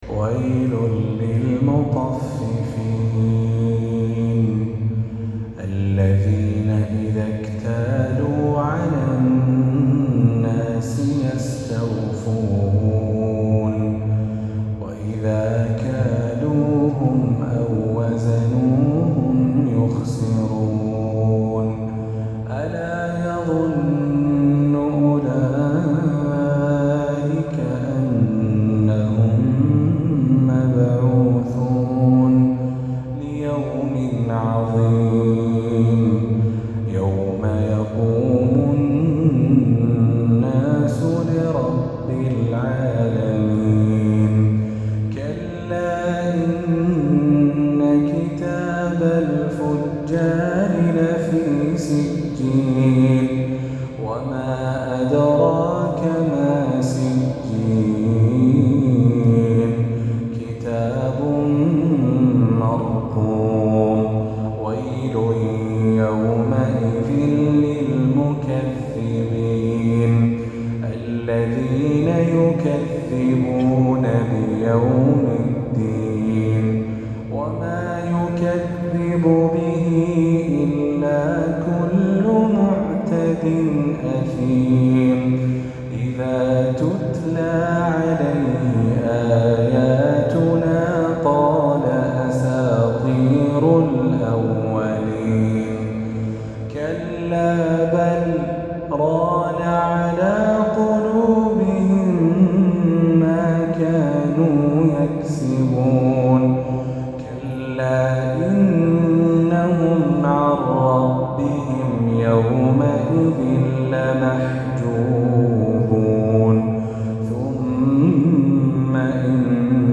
عشائية الأربعاء